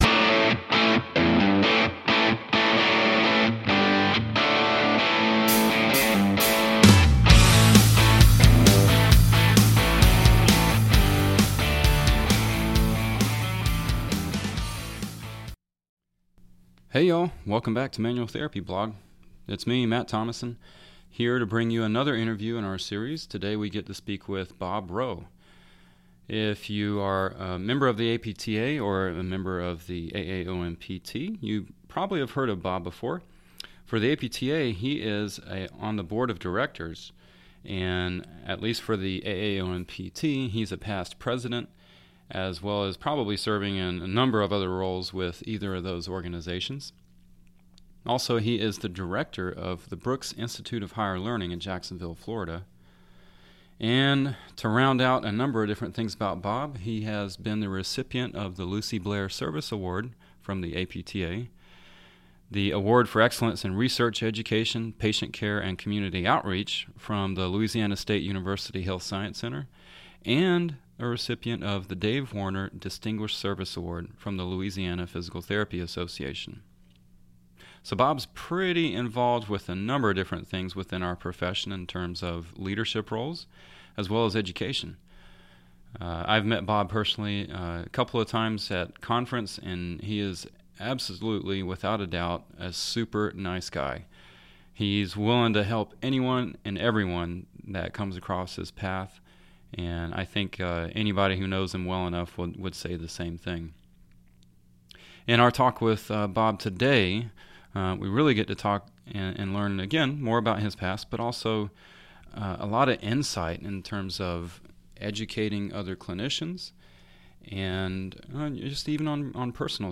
So, I hope you enjoy this conversation as much as I did!